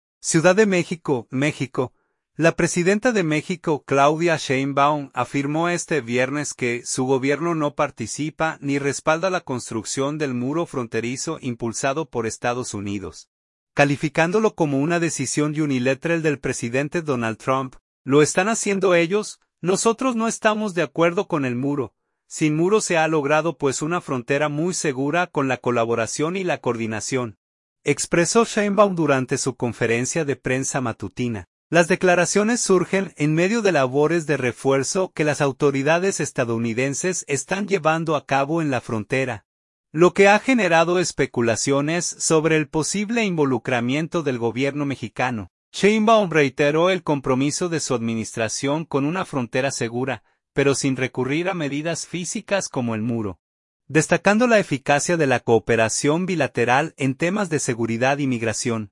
Sin muro se ha logrado pues una frontera muy segura con la colaboración y la coordinación”, expresó Sheinbaum durante su conferencia de prensa matutina.